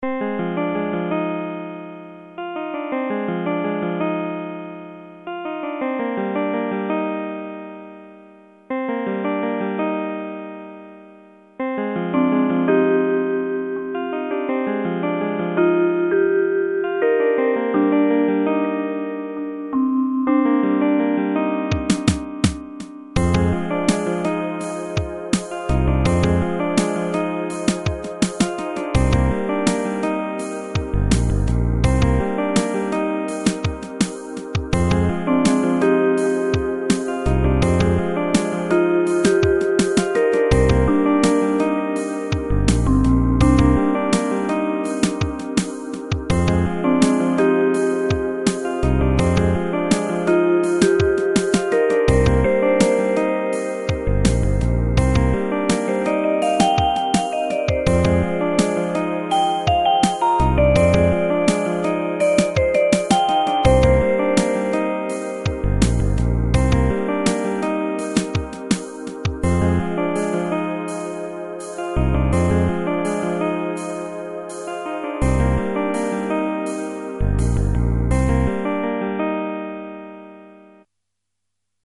Jazzy relaxing tune, suitable for a puzzle or thinking game.